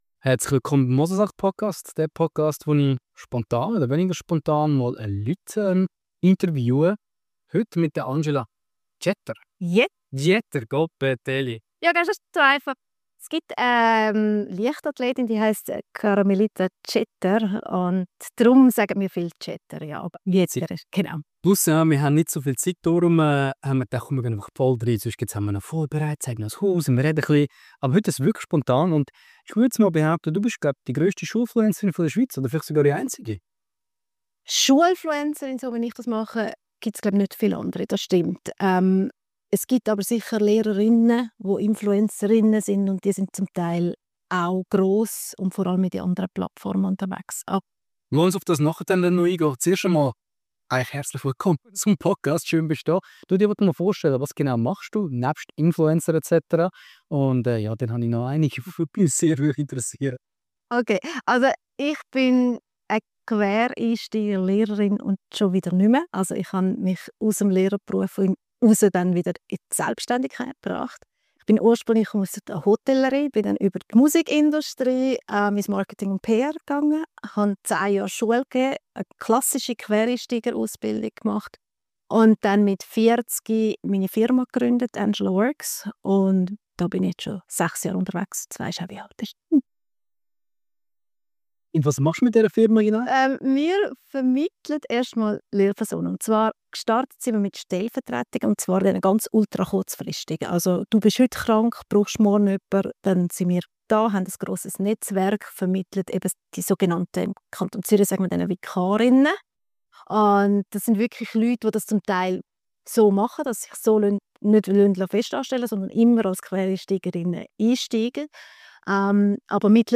Wir sprechen darüber, ob ihre Arbeit eine Nische oder ein riesiger Markt ist, welche Rolle LinkedIn für sie spielt und was es braucht, um als Selbstständige erfolgreich zu sein. Ein Gespräch über Sichtbarkeit, Mut und wie man als Schulfluencerin eine ganze Branche verändert.